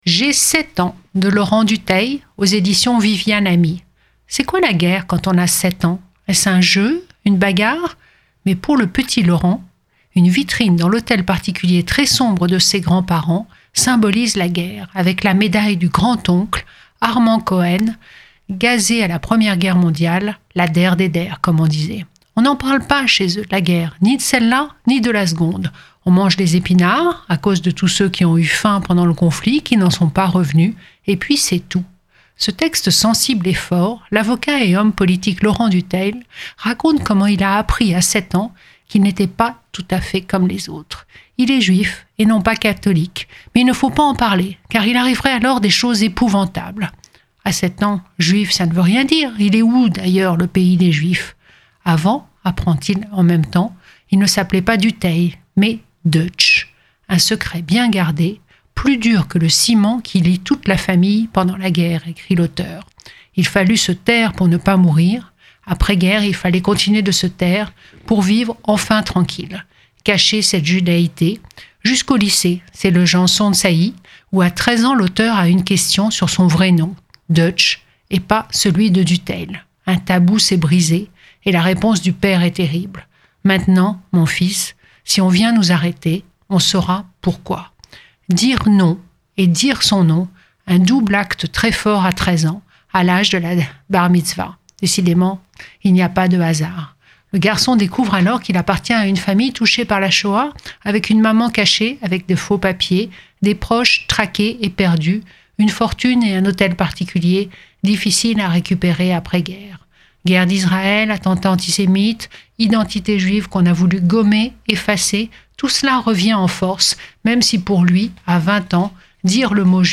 Chronique